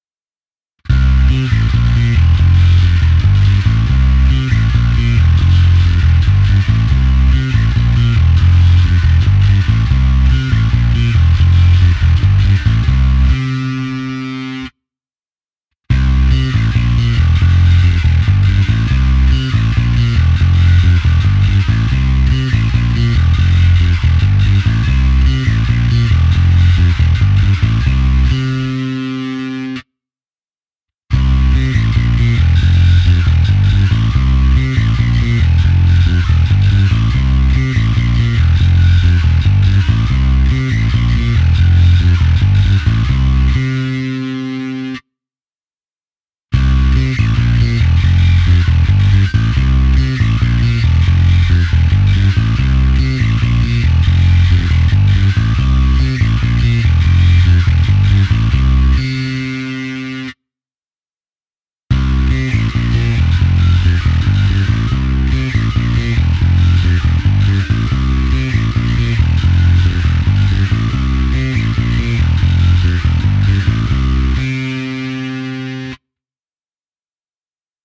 Bonusové nahrávky se simulací aparátu ve stejném pořadí jako výše (1-5)
Ukázka vintage zkreslení